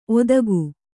♪ odagu